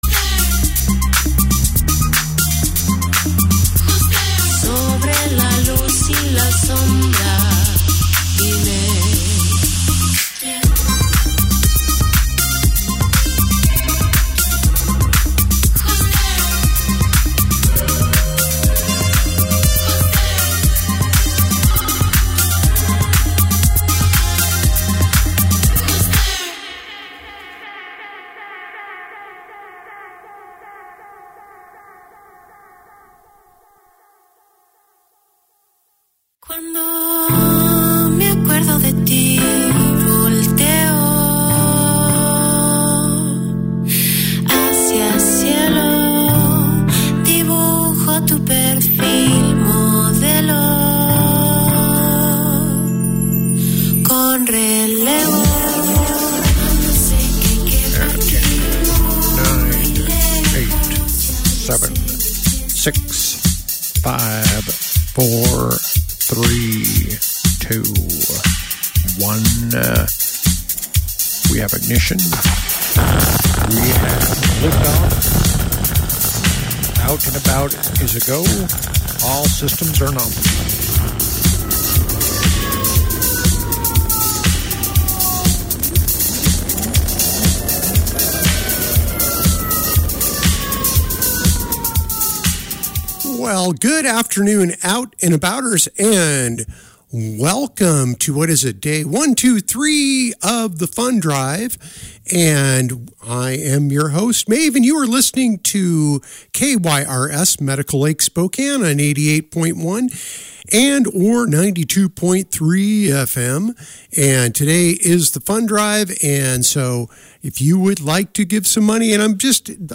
Yesterday, we raised $712 for KYRS! I was on the air for two hours, playing music and talking until I was quite hoarse!